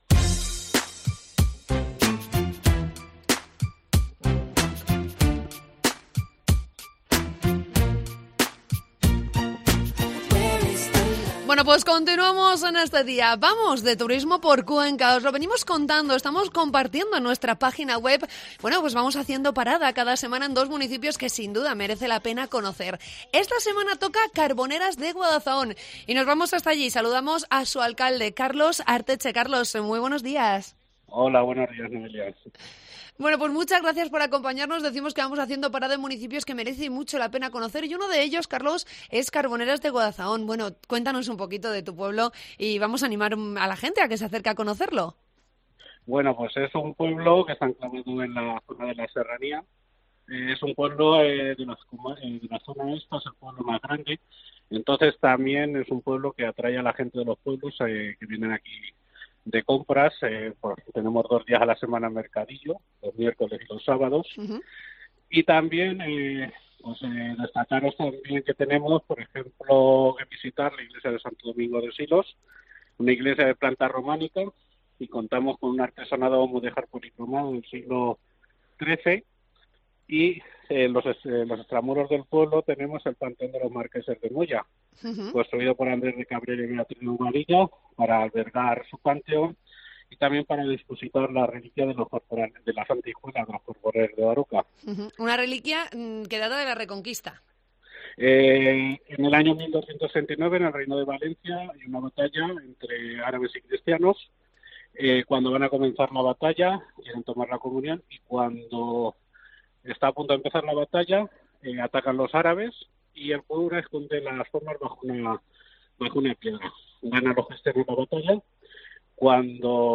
Entrevista con el alcalde de Carboneras de Guadazaón